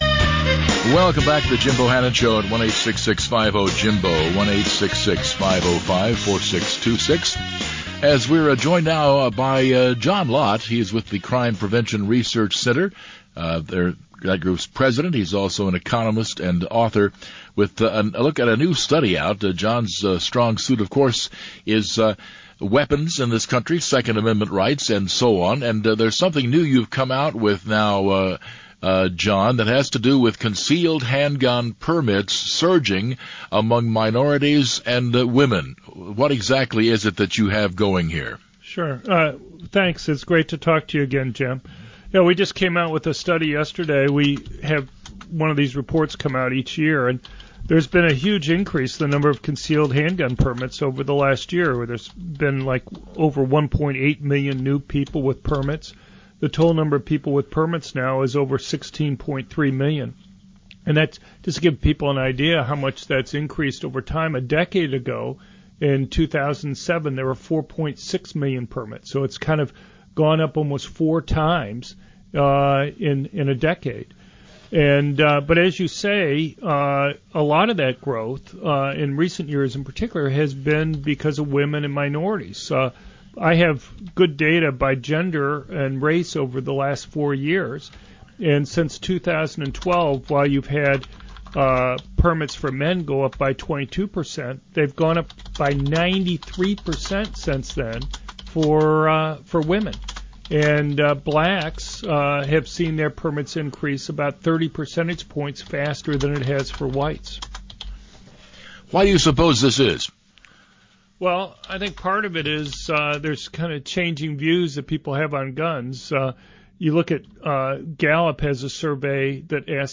Dr. John Lott again talked to Jim Bohannon about our new report on concealed handgun permits.